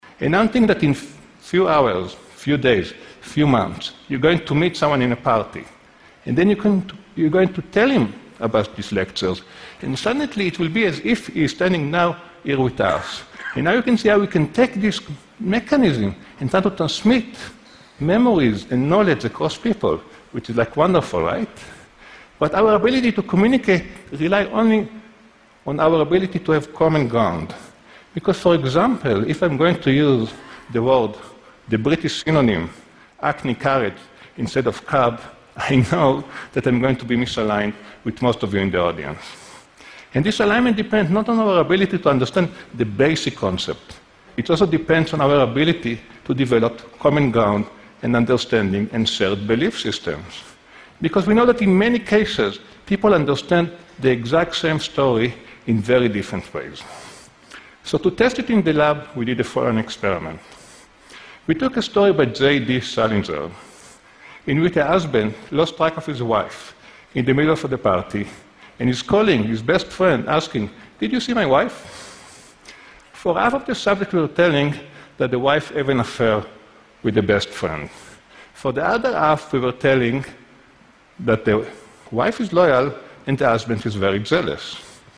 TED演讲:我们的大脑是如何交流的?(8) 听力文件下载—在线英语听力室